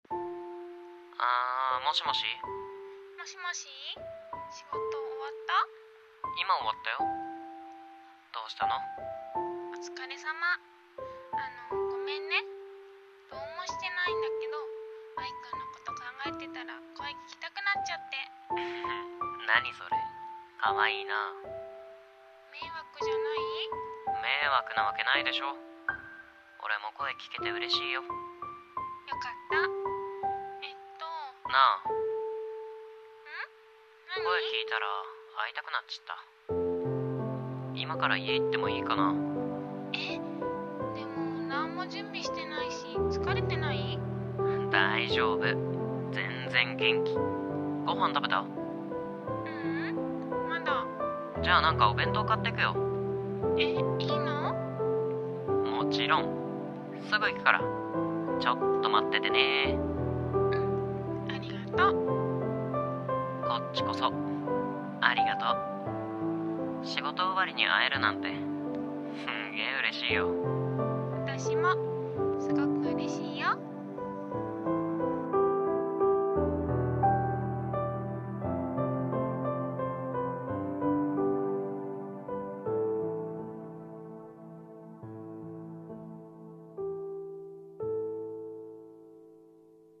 声劇【電話～声が聴きたくて～】※コラボ声劇